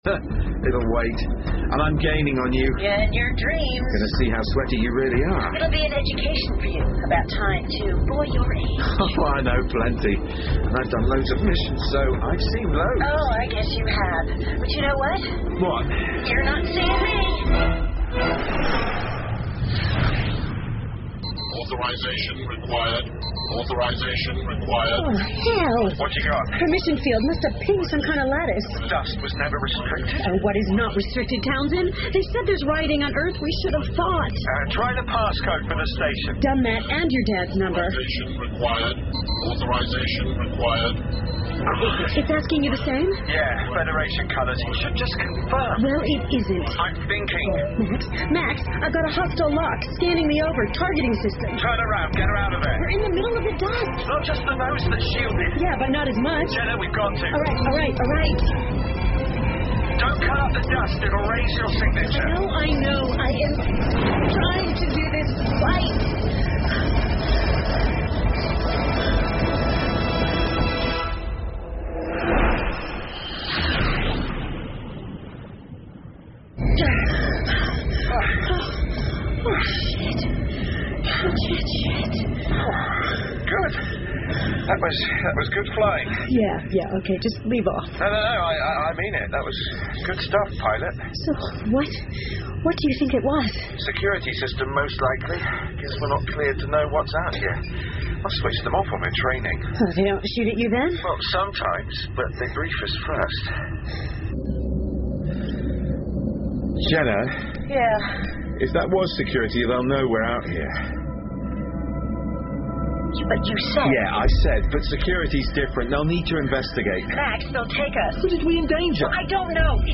英文广播剧在线听 Benedict Cumberbatch 09 听力文件下载—在线英语听力室